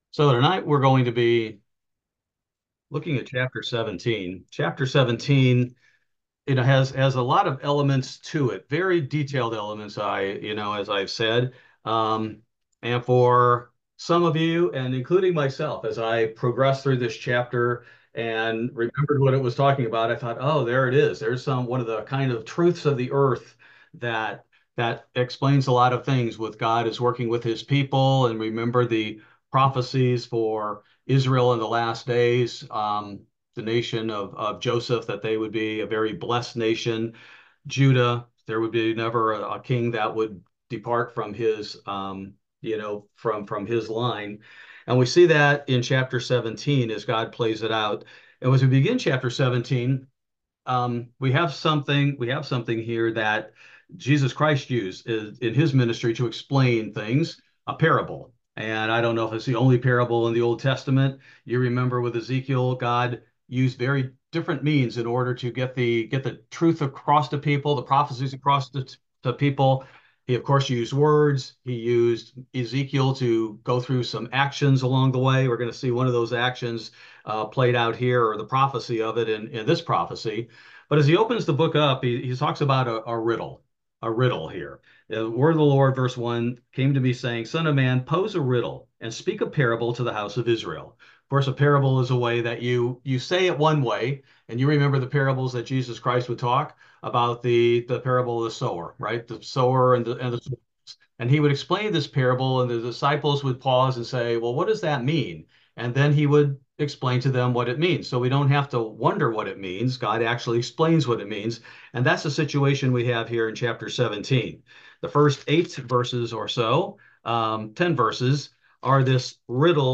This verse by verse Bible Study focuses primarily on Ezekiel 17: The Riddle and a World-Changing Prophecy